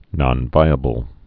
(nŏn-vīə-bəl)